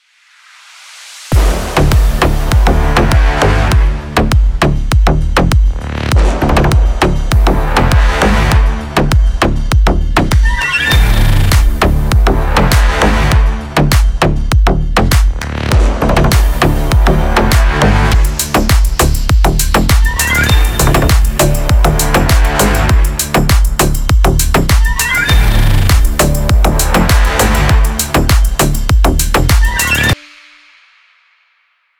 громкие
deep house
восточные мотивы
Club House
без слов
басы
G-House